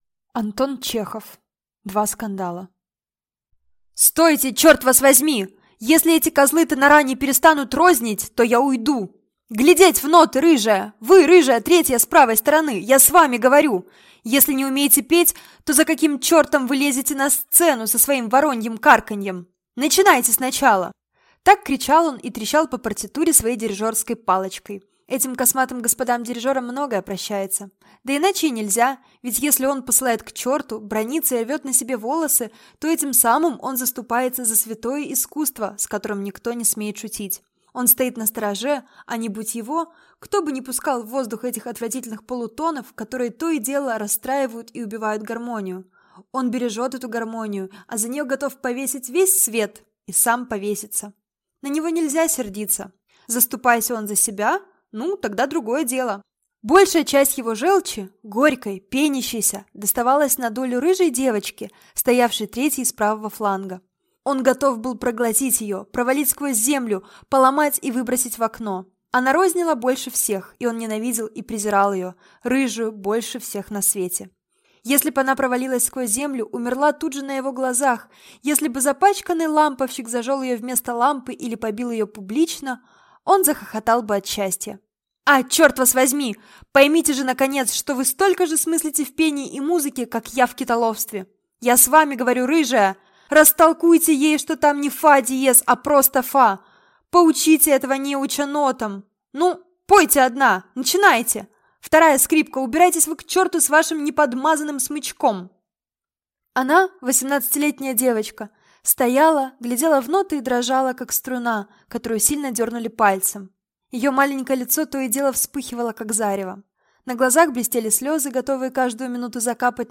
Аудиокнига Два скандала | Библиотека аудиокниг